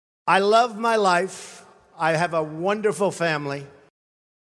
На этой странице собраны аудиозаписи с голосом Дональда Трампа: знаменитые высказывания, фрагменты выступлений и публичных речей.